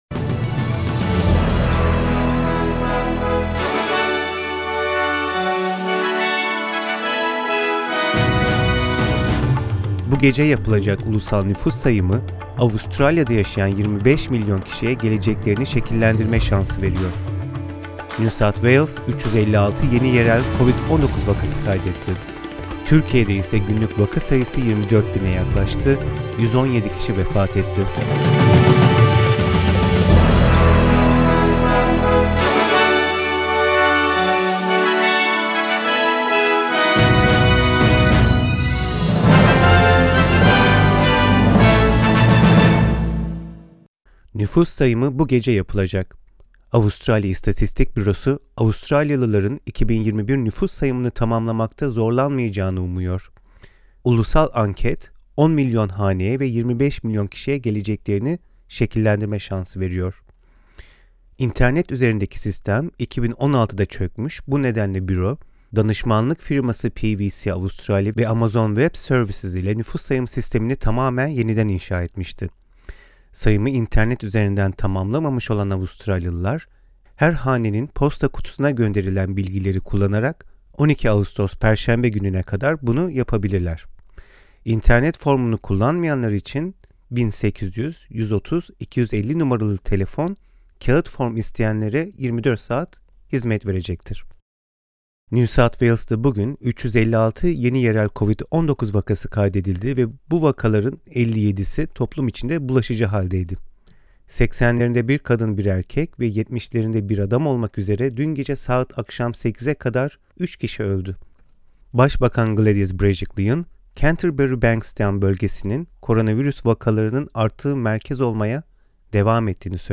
SBS Türkçe Haberler 10 Ağustos